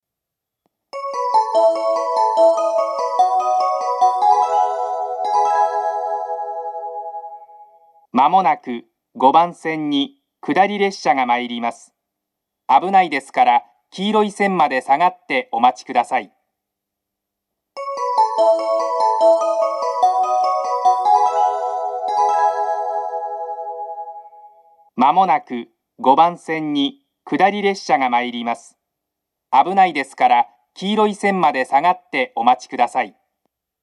放送はこの辺りでは珍しく、ラッパスピーカーではなく小丸VOSSから流れます。放送の音量はあまり大きくなく、ホームによってはスピーカーの位置が高いです。
５番線下り接近放送 一部の貨物列車が入線するようです。
iwanuma-5bannsenn-kudari-sekkinn1.mp3